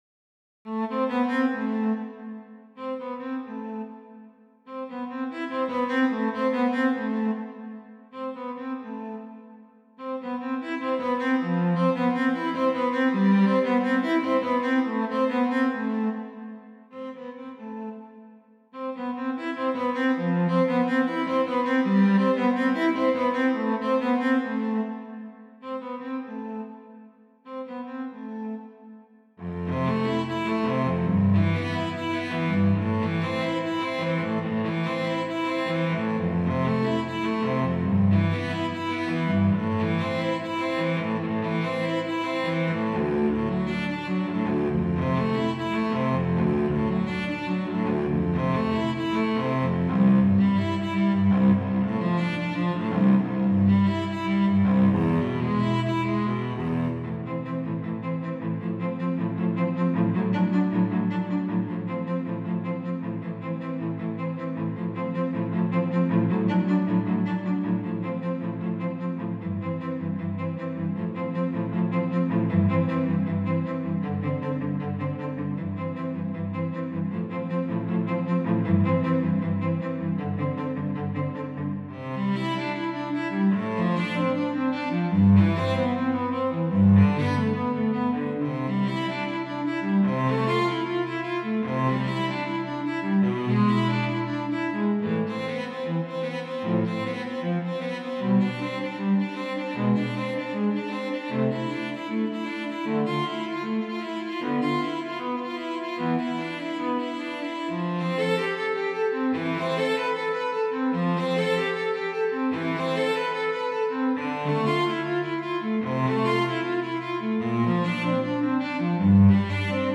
solo viola music